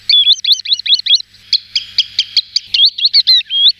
Chevalier culblanc
Tringa ochropus